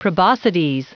Prononciation du mot proboscides en anglais (fichier audio)
Prononciation du mot : proboscides